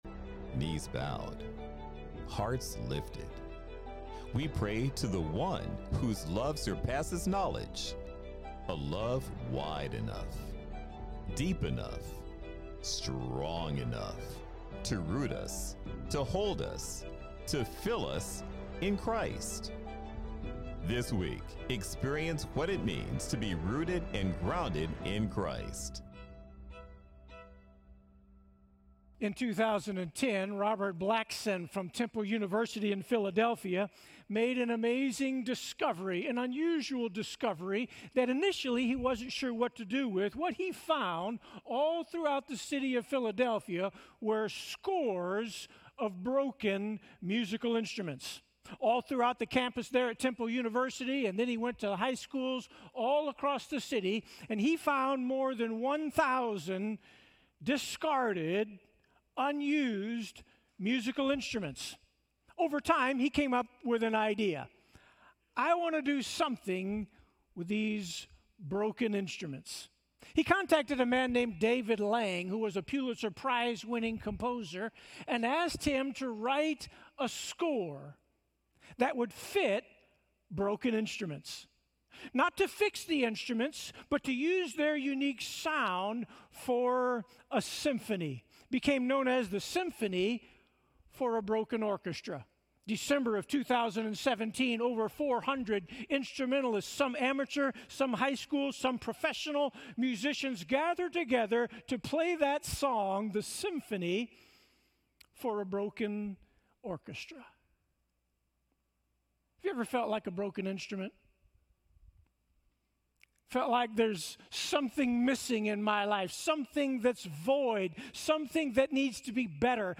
Sermons - Sunnyvale FBC